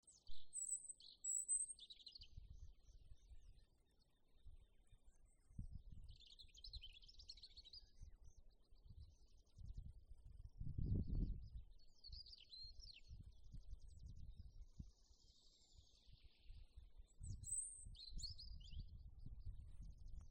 Sarkanrīklīte, Erithacus rubecula
StatussDzied ligzdošanai piemērotā biotopā (D)